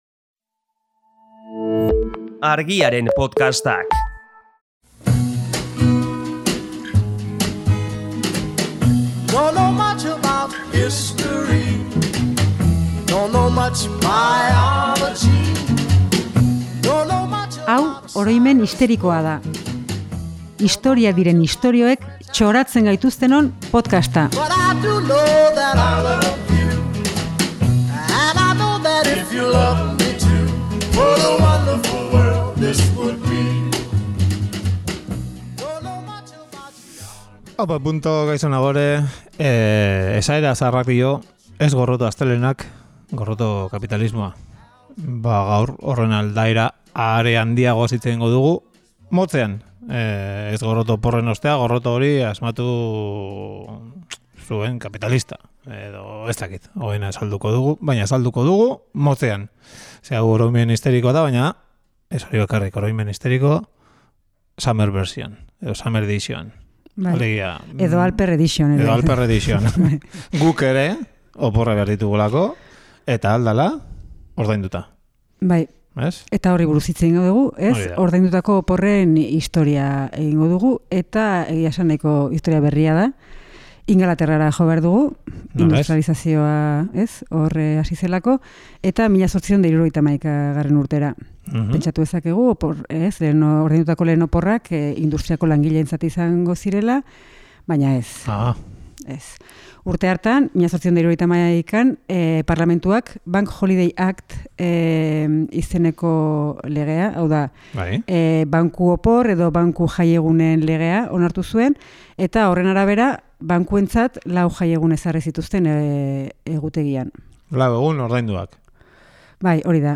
Honako abesti hau erabili dugu gaurko saioan: